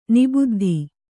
♪ nibuddhi